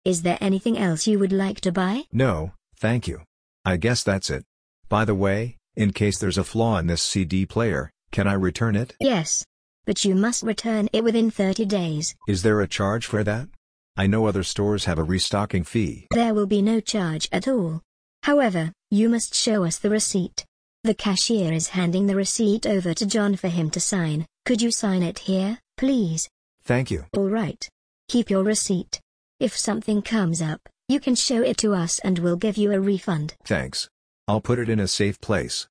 مکالمات واقعی زبان انگلیسی (خريد): رسید رو جای امنی نگه دار.